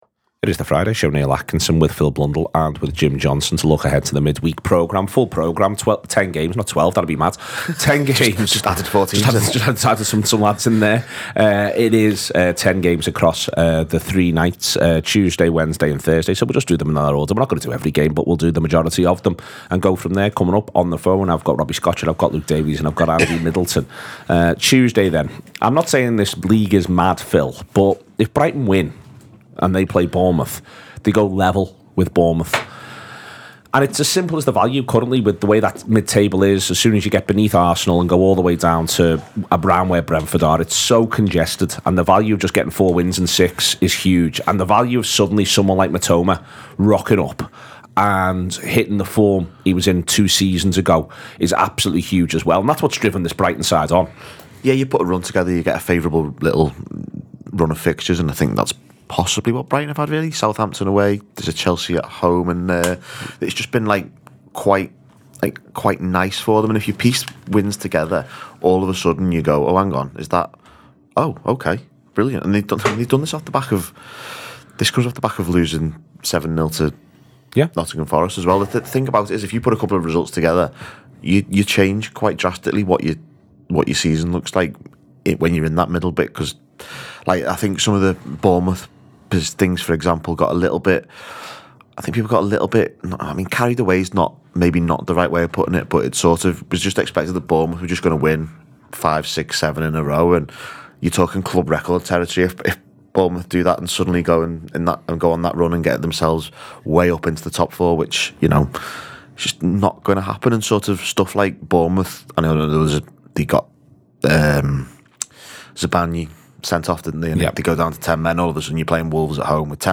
The Anfield Wrap’s preview show ahead of another massive weekend of football.